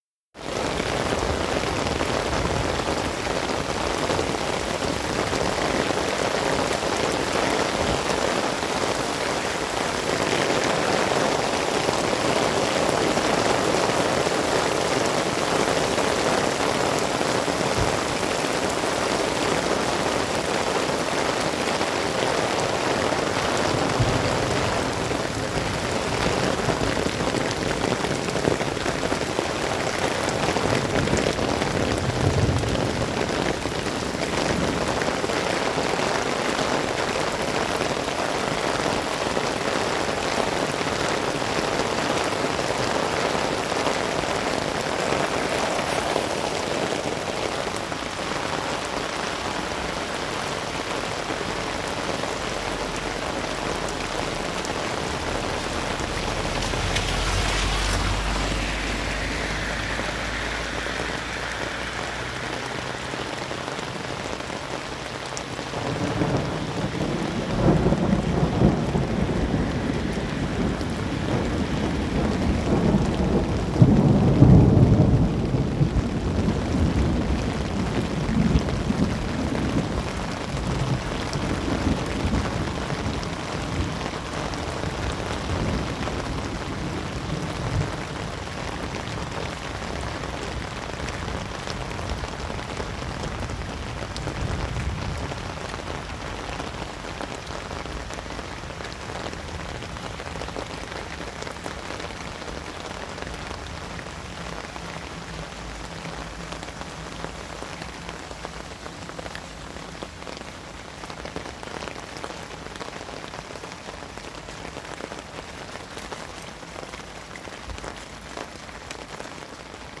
Umbrella walk
Category 🌿 Nature
beep door Lightning Rain staircase Storm Thunder umbrella sound effect free sound royalty free Nature